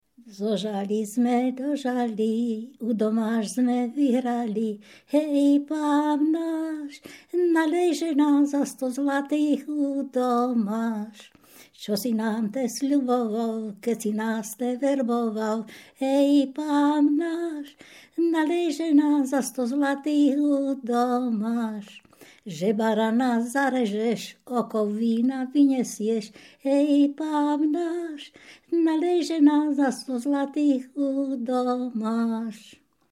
Popis sólo ženský spev bez hudobného sprievodu
Predmetová klasifikácia 11.2. Piesne späté s kalendárnym obyčajovým cyklom